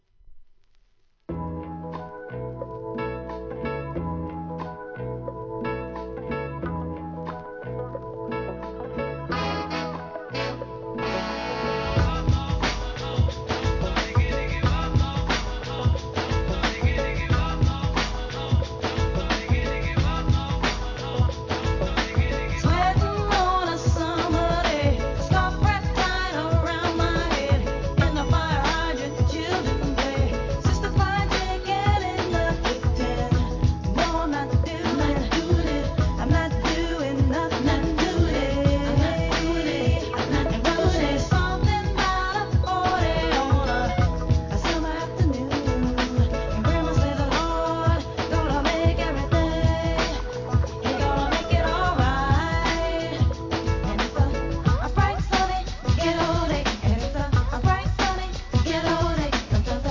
HIP HOP/R&B
レゲエ・テイストの